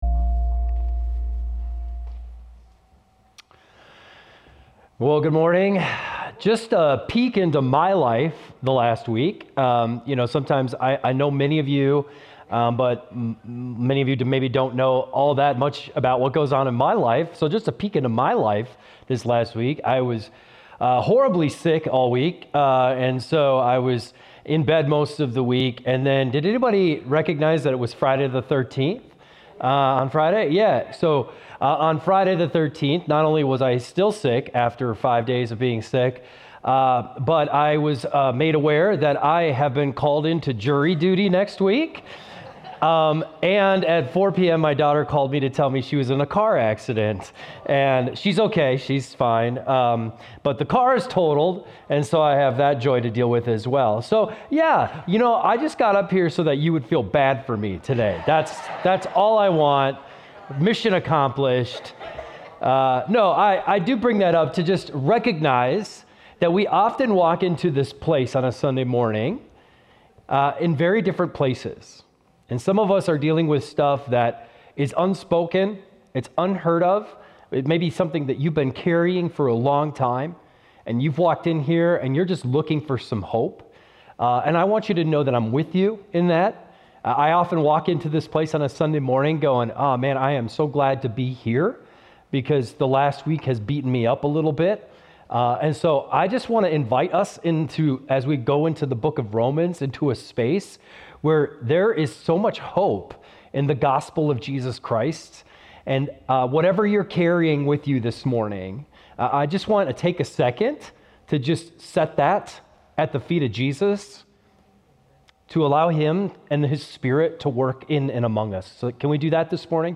keyboard_arrow_left Sermons / Romans Series Download MP3